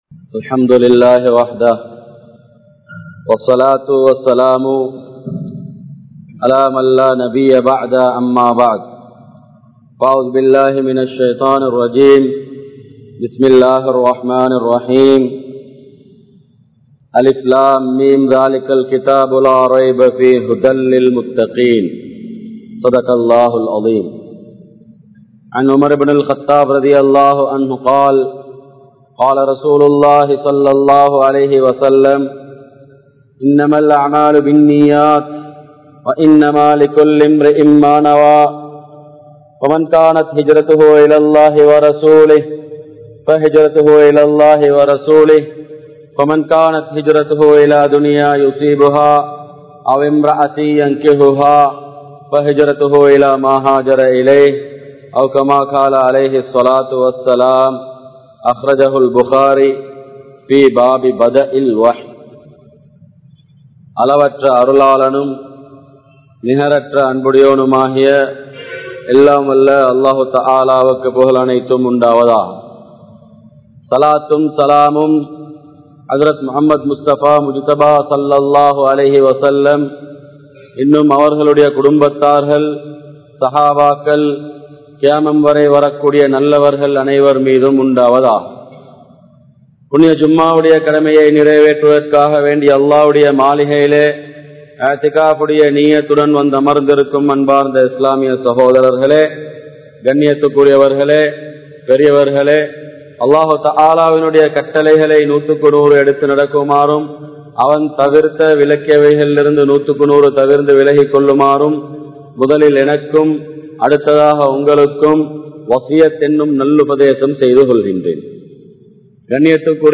Noanpum Science`um | Audio Bayans | All Ceylon Muslim Youth Community | Addalaichenai